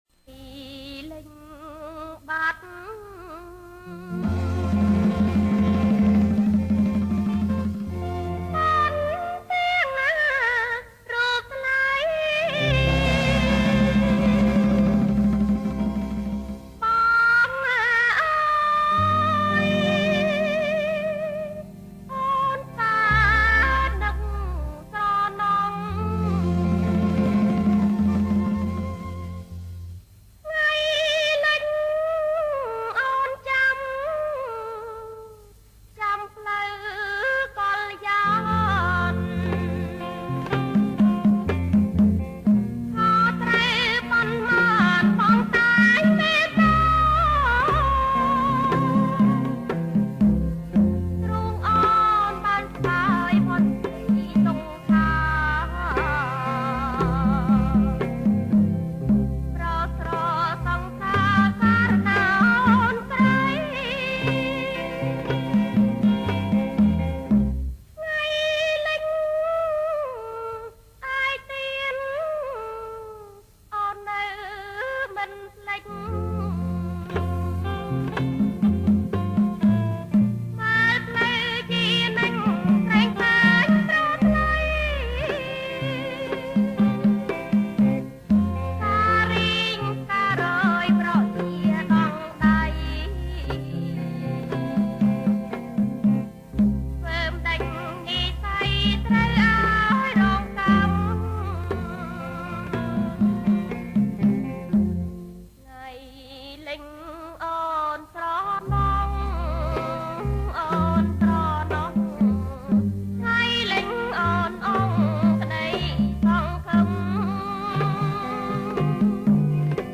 ប្រគំជាចង្វាក់ Bolero